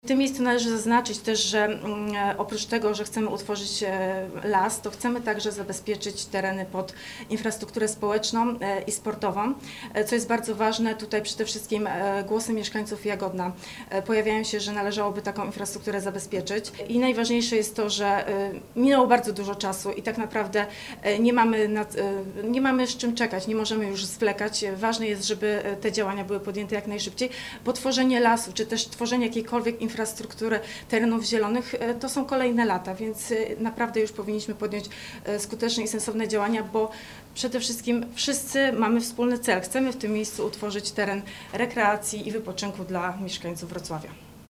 Platforma Obywatelska zorganizowała konferencję w sprawie poparcia Zielonego Klina Południa Wrocławia.
– W wyniku wielu spotkań i rozmów, zostało potwierdzone, że na tym terenie może powstać las społeczny – mówi Edyta Skuła Radna Rady Miejskiej Wrocławia.